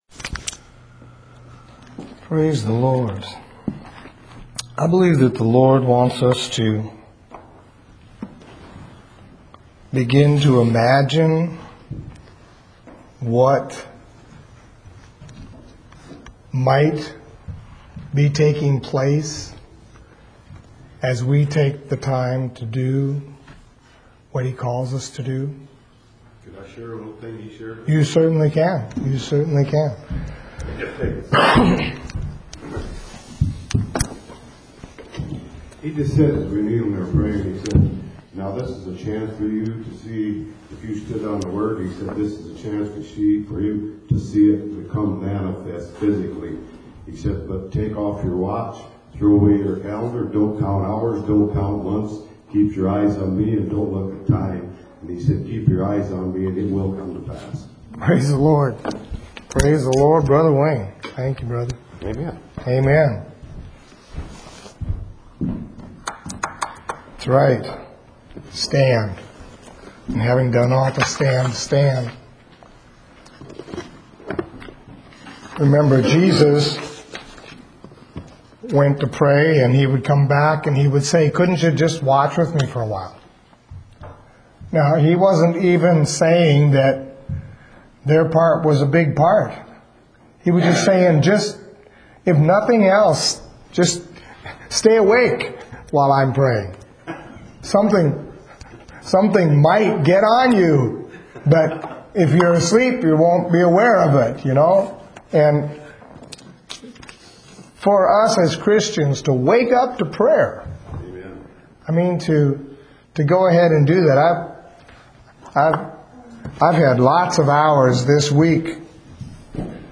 Longer sermons are broken up into smaller...